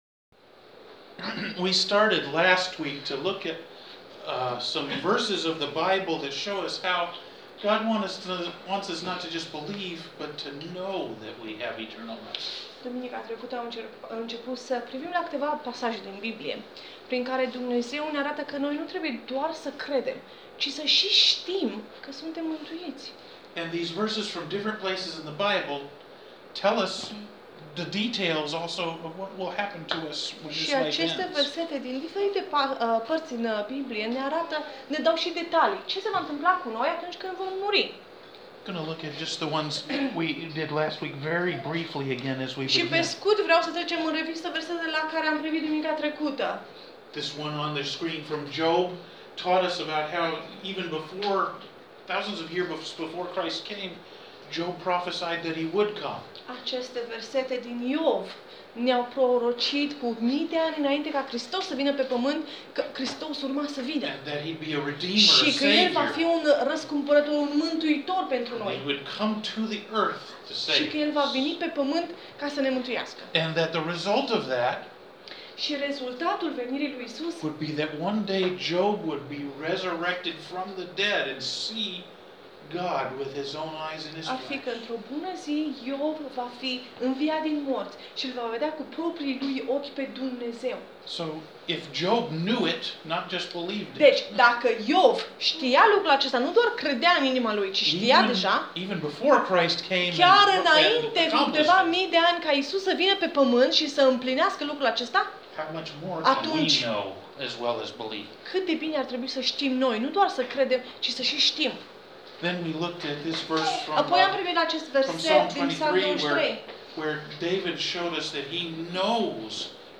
Credeți și știți, pt. II, sermon audio | Biserica Harul Domnului